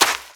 STEPS Sand, Run 24.wav